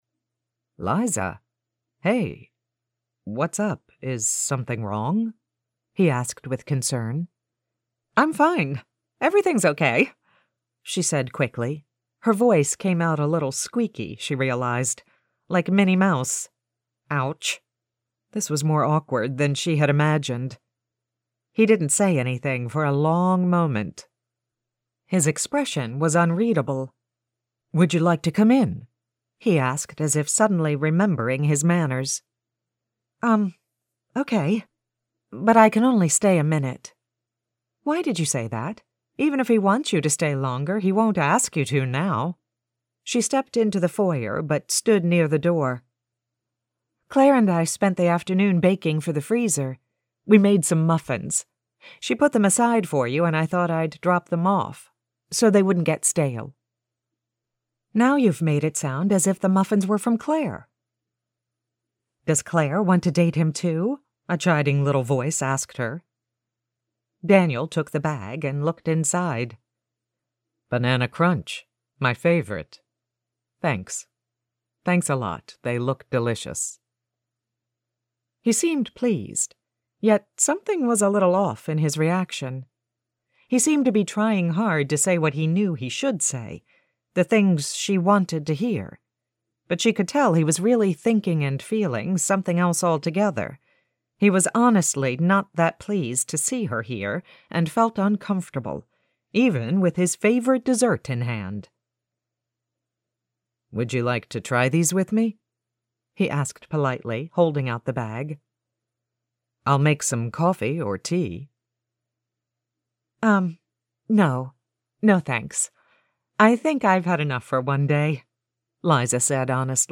Narration Samples for Harlequin — Potomac Talking Books
3:23 1. Female Sample #1 4:34 2.
1.+Female+Sample+#1.mp3